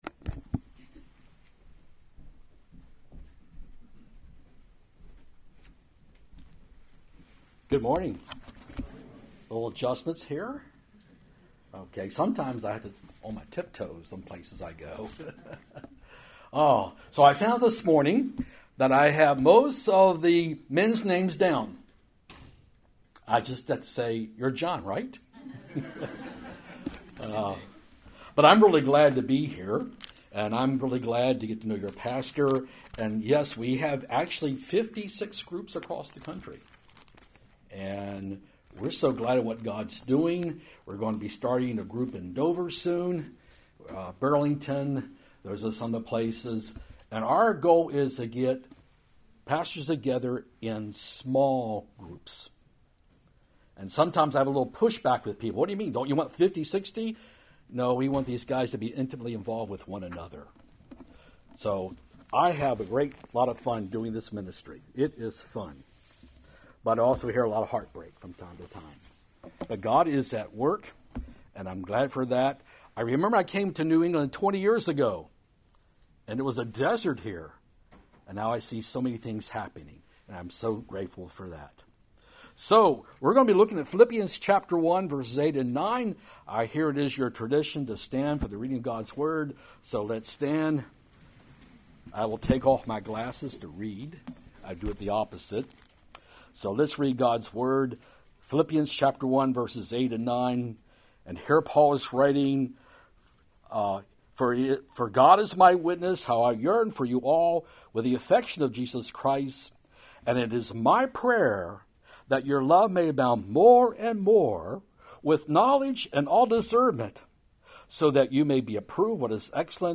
January 21, 2017 Guest Speaker Sermons series Weekly Sunday Service Save/Download this sermon Philippians 1:8-11 Other sermons from Philippians 8 For God is my witness, how I yearn for you all […]